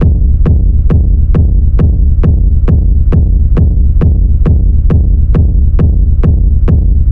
• Techno Sub Kick Black Delayed.wav
Techno_Sub_Kick_Black_1_-1-1_a6Z.wav